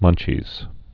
(mŭnchēz)